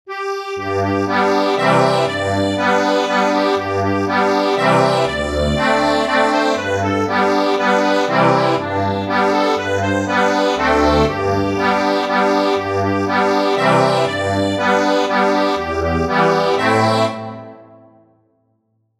Instrument:  Accordion
Easy accordion arrangement plus lyrics.